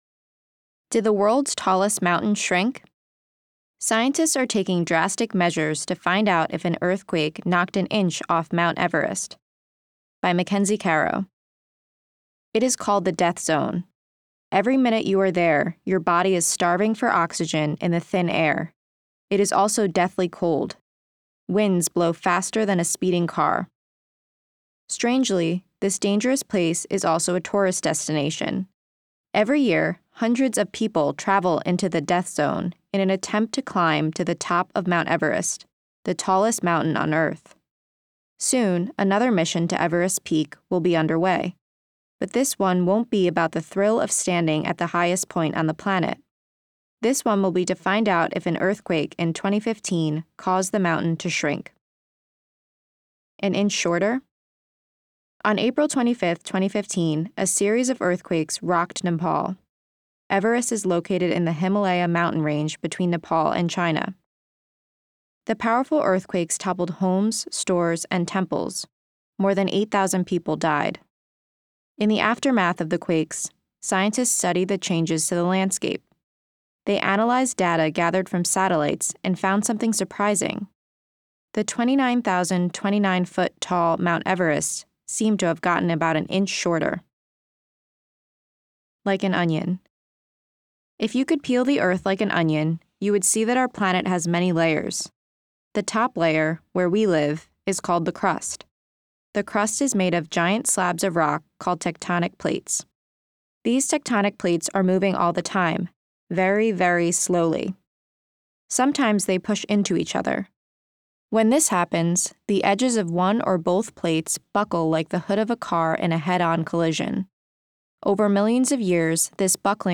audio version while students follow along in their printed issues.